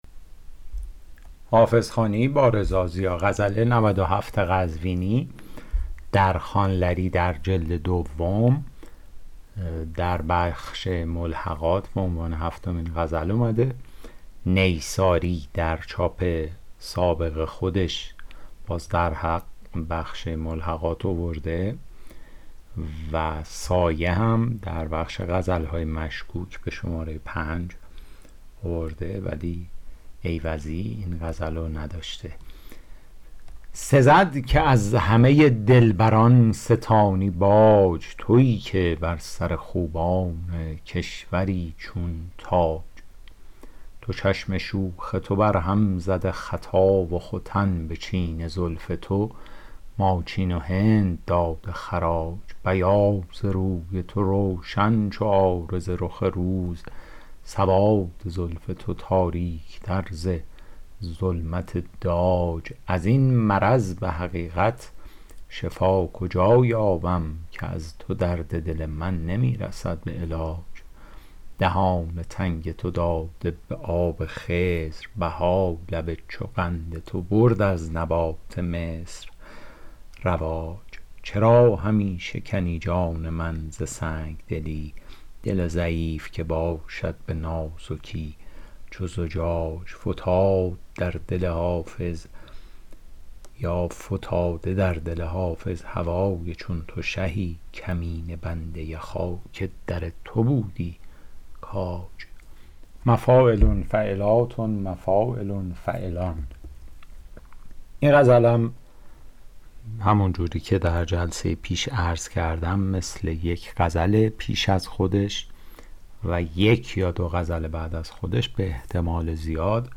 شرح صوتی غزل شمارهٔ ۹۷